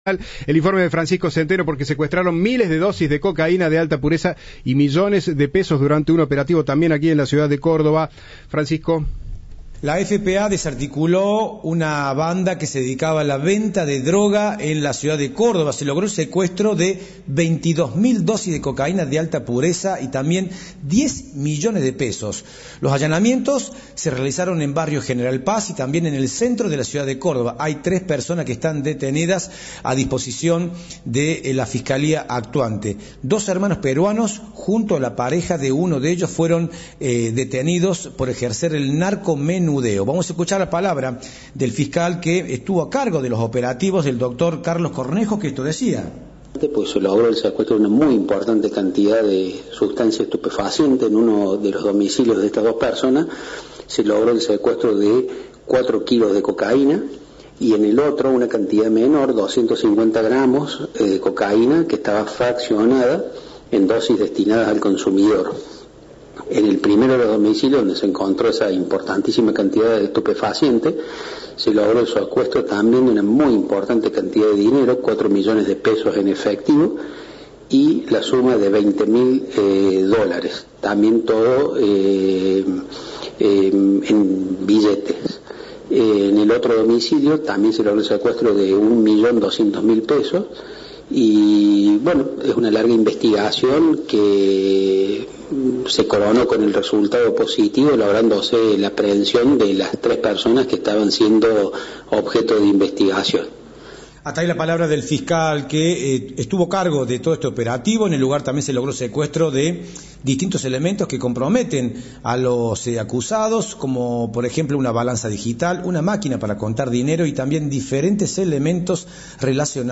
El fiscal Carlos Cornejo dijo a Cadena 3 que se trata de una larga investigación "que se coronó con el resultado positivo, lográndose la aprehensión de las tres personas en cuestión".
Informe